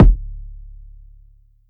wrettt_kik.wav